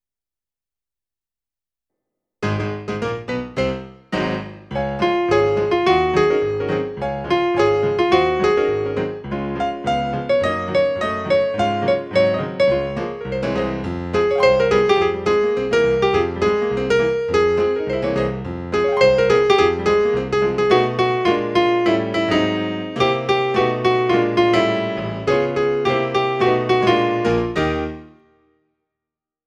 ALTO: